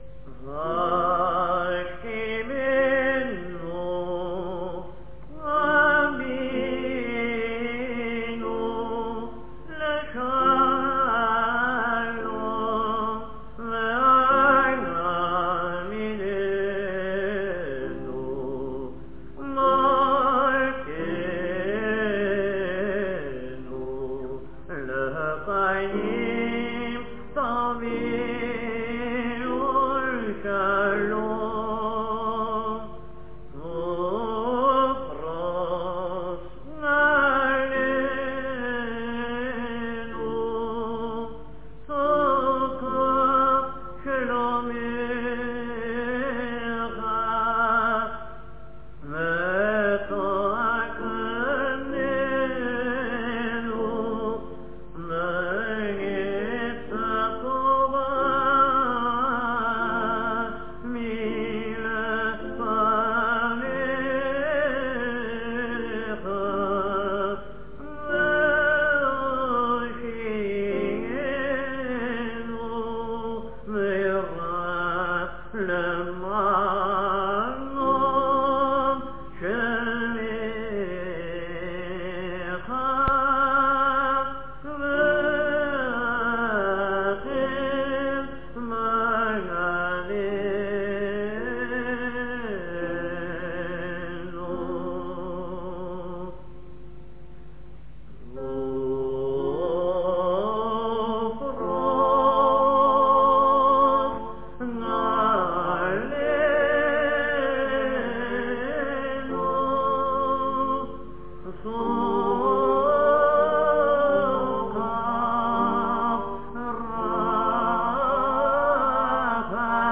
More festive